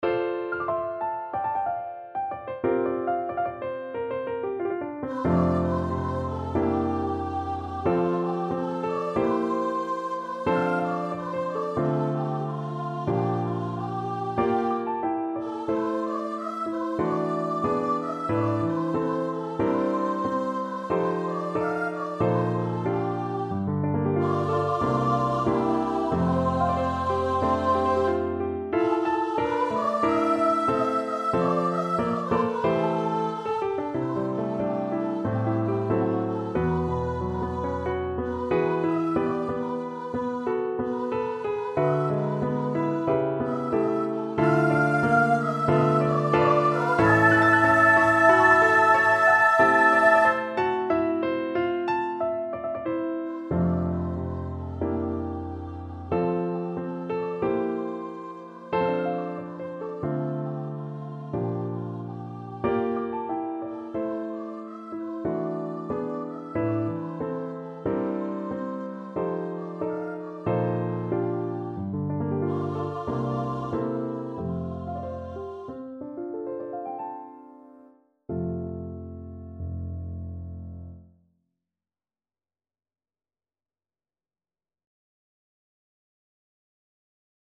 Free Sheet music for Soprano Voice
Soprano Voice
F major (Sounding Pitch) (View more F major Music for Soprano Voice )
Allegro Moderato = 92 (View more music marked Allegro)
Classical (View more Classical Soprano Voice Music)